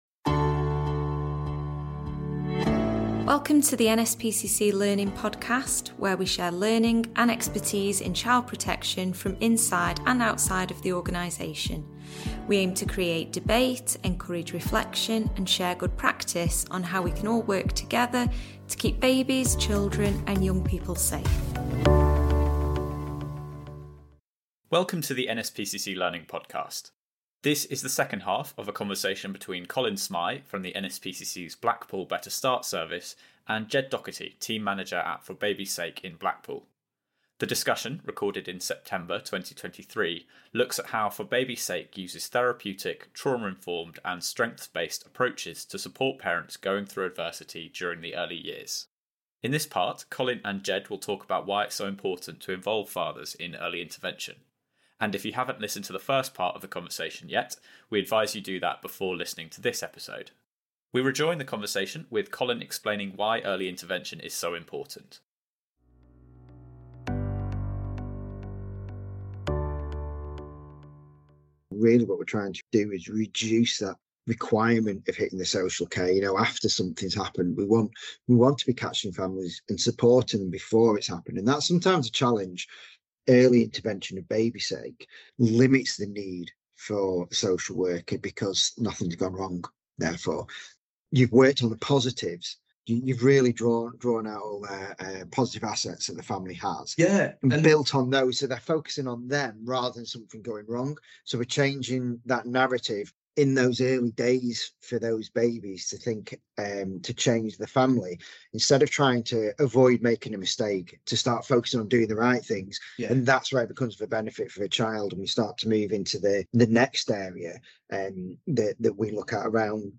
Part two of a podcast discussion about how therapeutic and trauma-informed early intervention services can support families through adversity and give children and young people the best start in life.